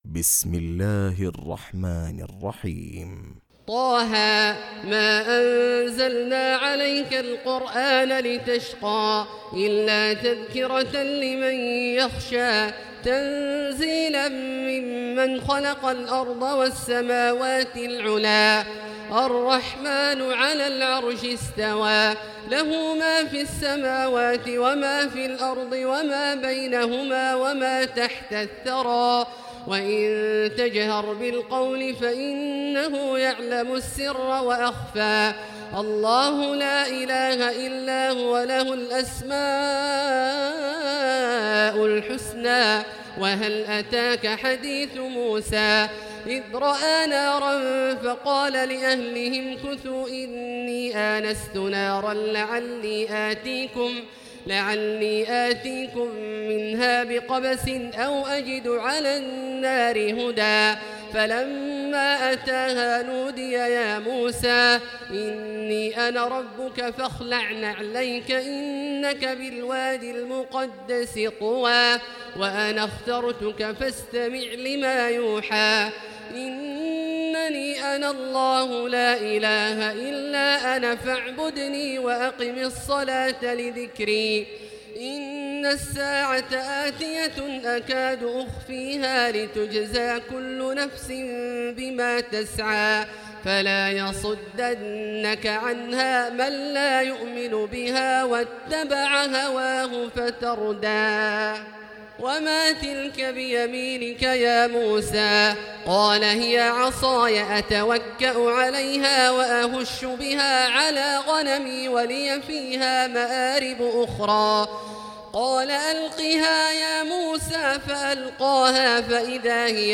تراويح الليلة الخامسة عشر رمضان 1439هـ سورة طه كاملة Taraweeh 15 st night Ramadan 1439H from Surah Taa-Haa > تراويح الحرم المكي عام 1439 🕋 > التراويح - تلاوات الحرمين